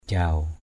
/ʥaʊ/ 1.